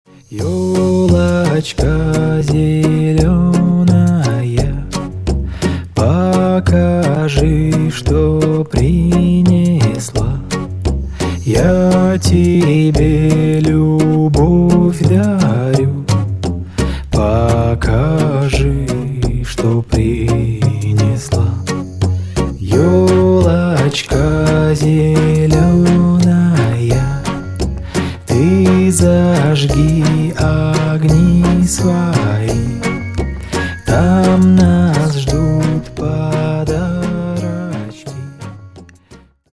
Поп Музыка
новогодние # спокойные